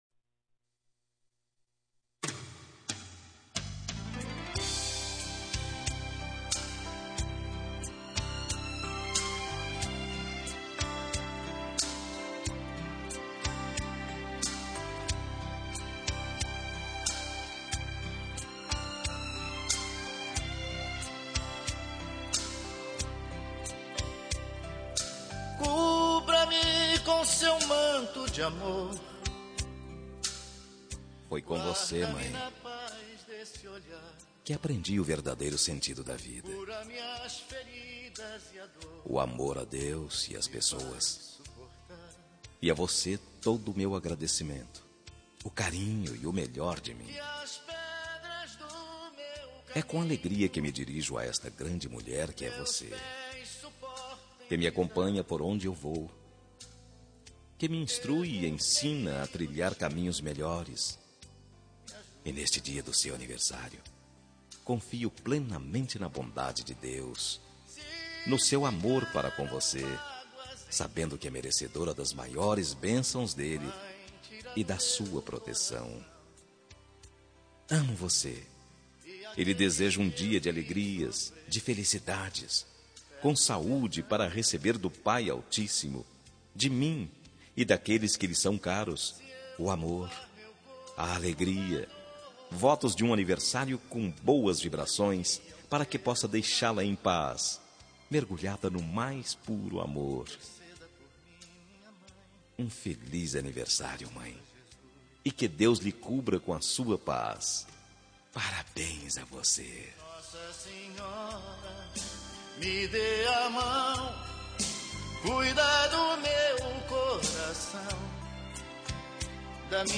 Telemensagem de Aniversário de Mãe – Voz Masculina – Cód: 1448 – Religiosa